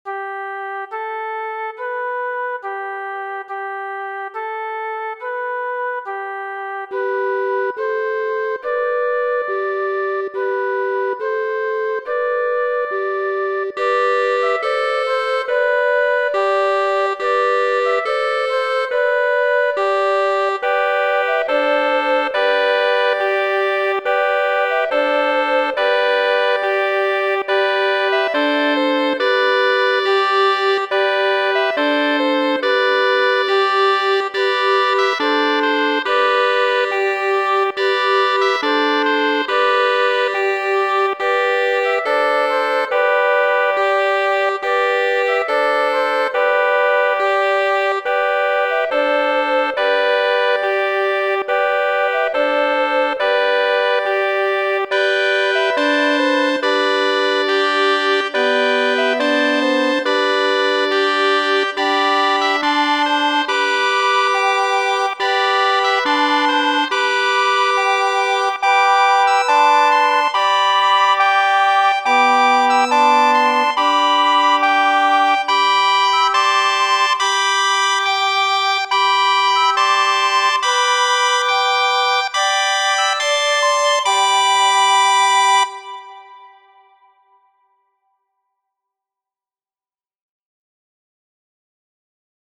Frato Javo, franca popolkanto